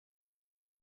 voicepowers_shout01a_0010f4b0_1